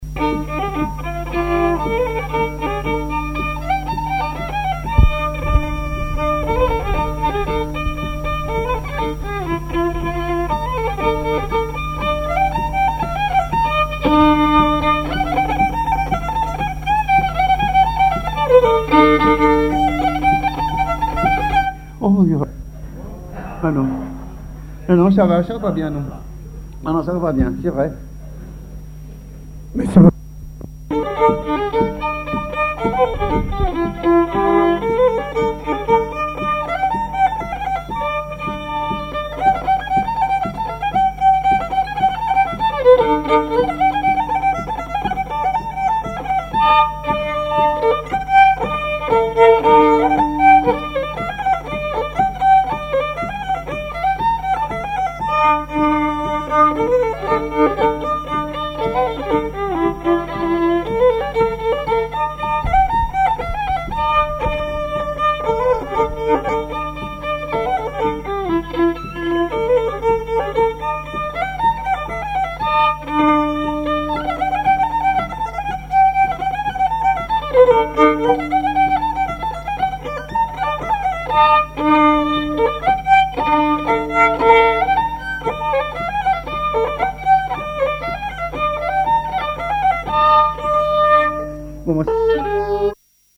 danse : pas d'été
collectif de musiciens pour une animation à Sigournais
Pièce musicale inédite